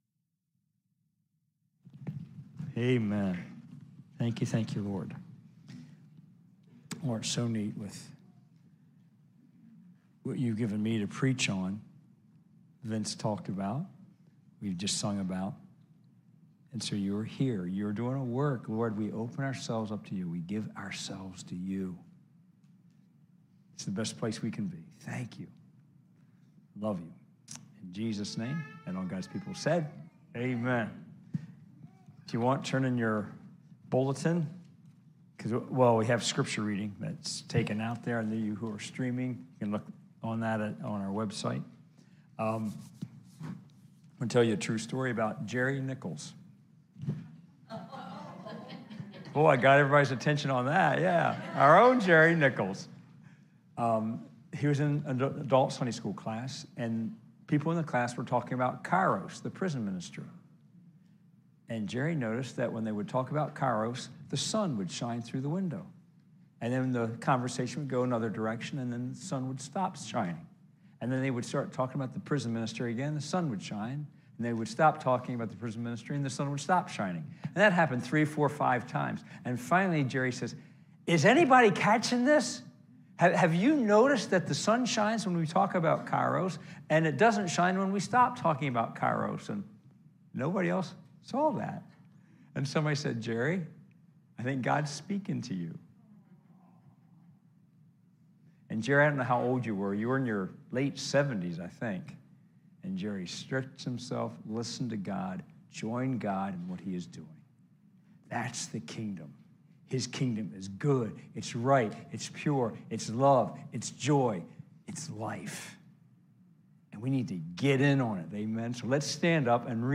John 5 – Your Kingdom Come, Your Will Be Done Replay: May 22, 2022 live stream worship service.
5-22-22-Sermon-Audio.mp3